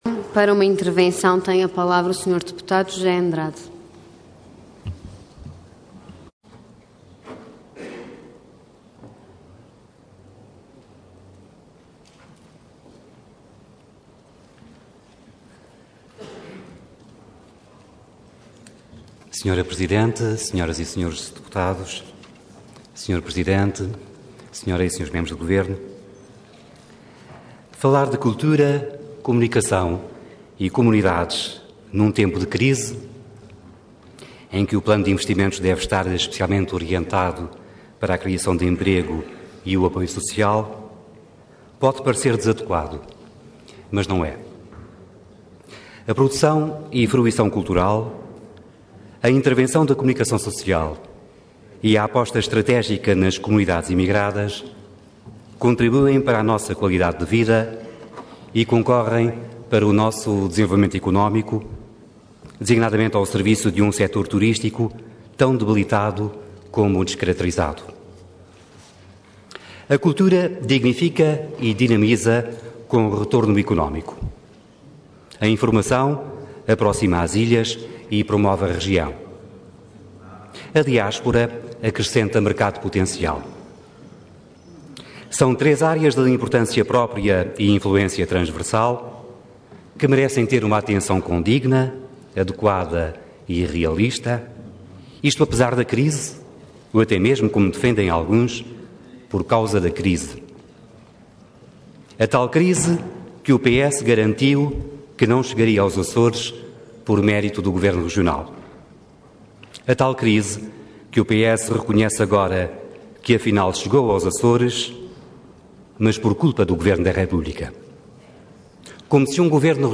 Intervenção Intervenção de Tribuna Orador José Andrade Cargo Deputado Entidade PSD